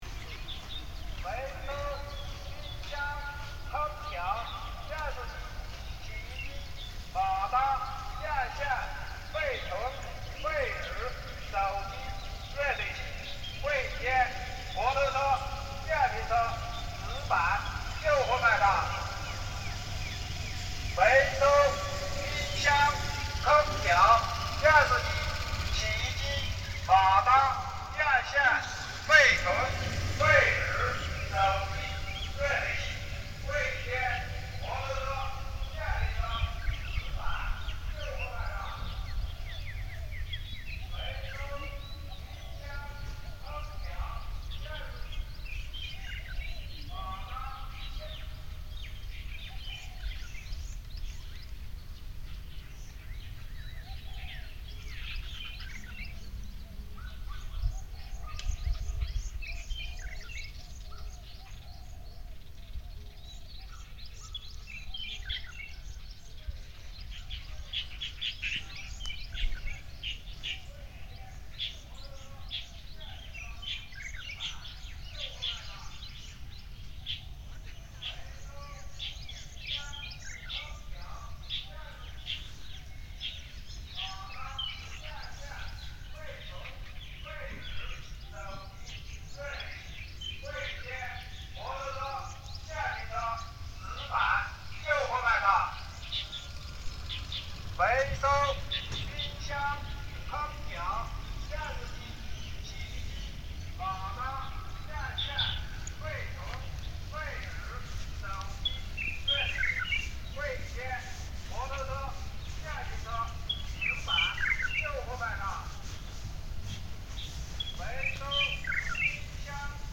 A home appliances collector advertising in Xinqi village, Suzhou, China.